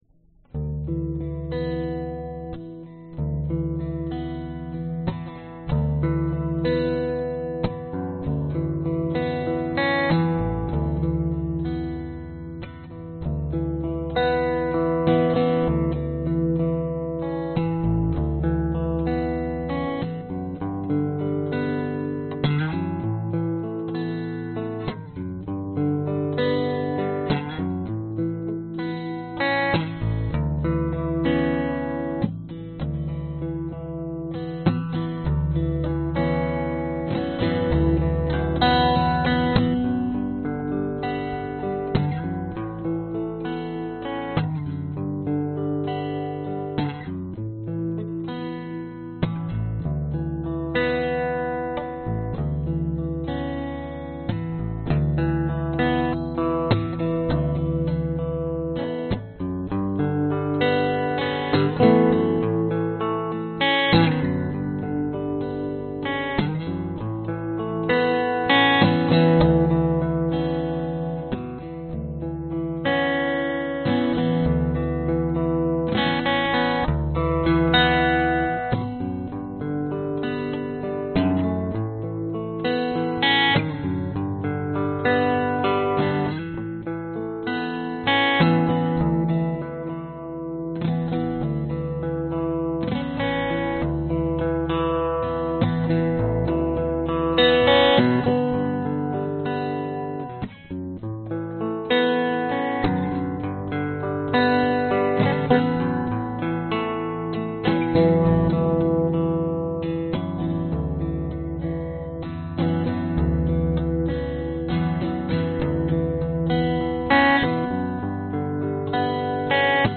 故障
描述：出现一个小故障
Tag: 奇怪 波形 音乐